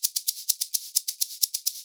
Index of /90_sSampleCDs/USB Soundscan vol.36 - Percussion Loops [AKAI] 1CD/Partition B/22-130SHAKER